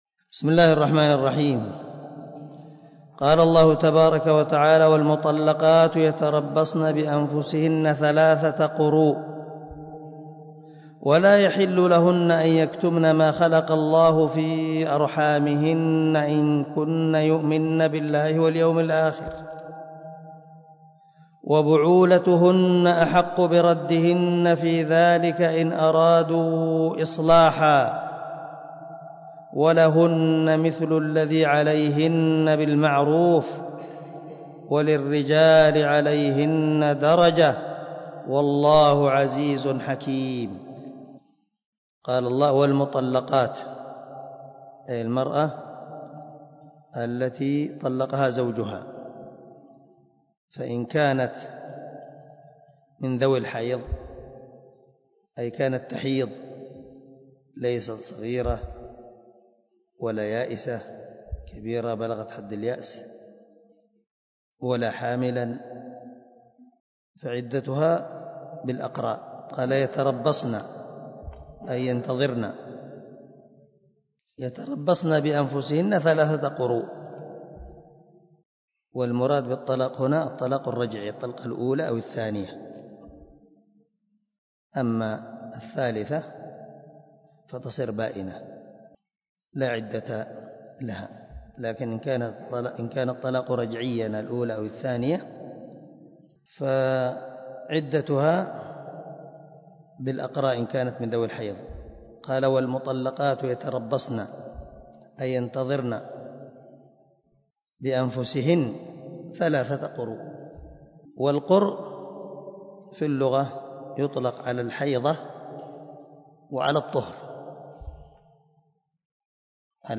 114الدرس 104 تفسير آية ( 228 ) من سورة البقرة من تفسير القران الكريم مع قراءة لتفسير السعدي
دار الحديث- المَحاوِلة- الصبيحة.